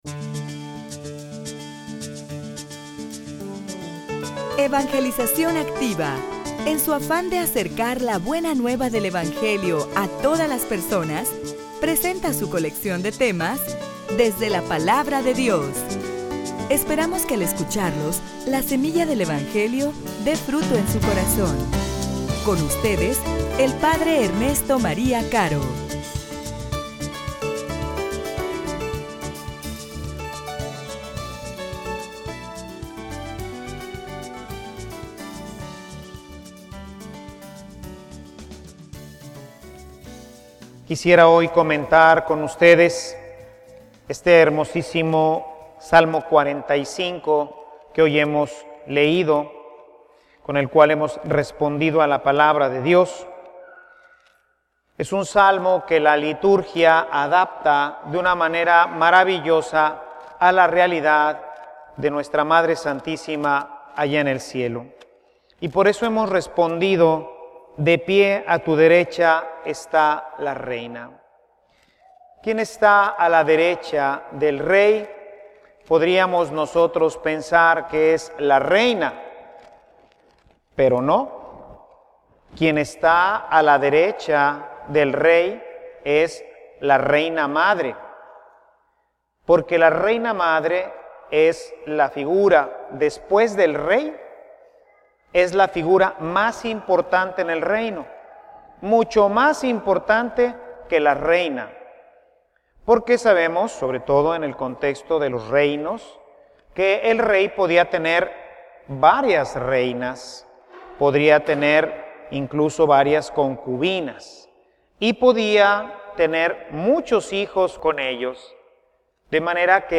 homilia_La_Reina_del_Cielo.mp3